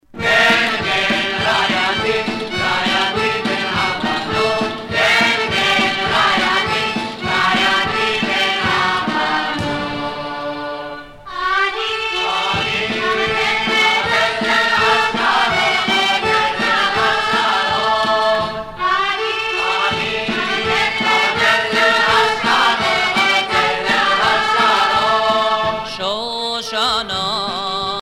Chants patriotiques et de retour